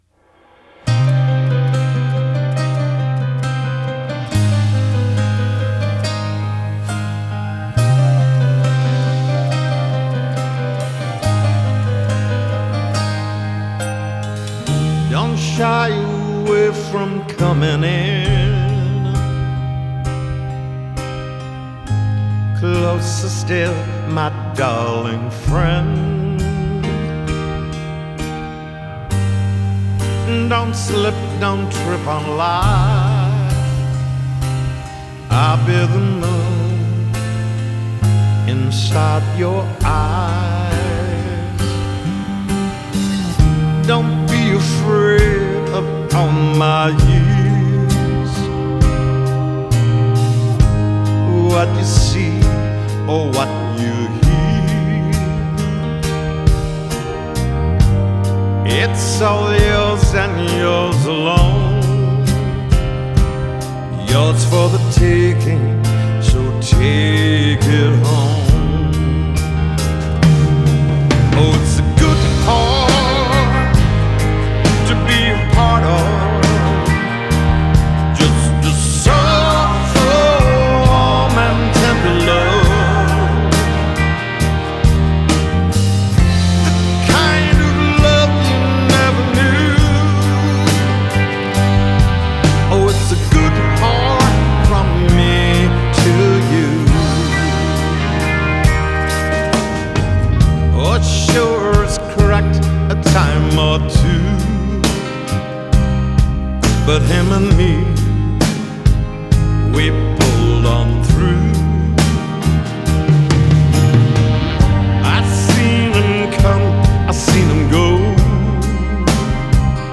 recorded at The Village in Los Angeles
Genre: Pop Rock, Classic Rock, Soft Rock